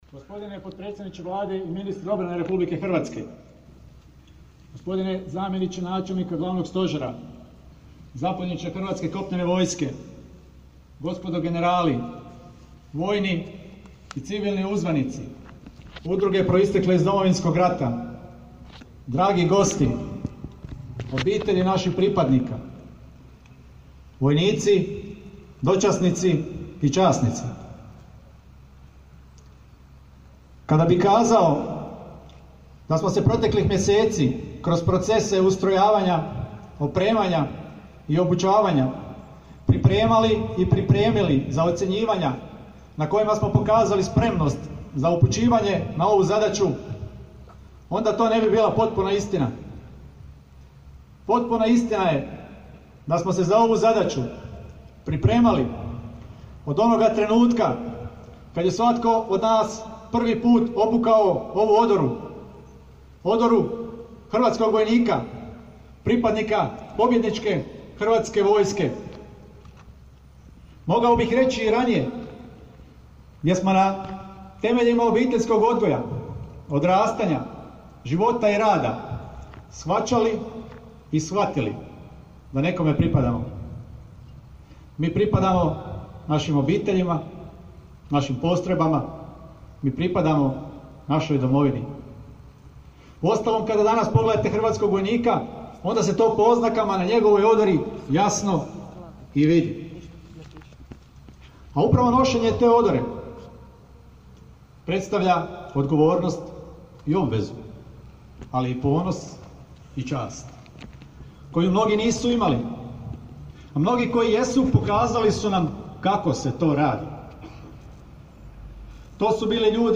U vojarni „Bilogora“ u Bjelovaru u utorak, 22. listopada 2019. održana je svečanost ispraćaja 5. hrvatskog kontitngenta (HRVCON-a) eFPBG – POL u NATO aktivnost Ojačane prednje prisutnosti u Republiku Poljsku.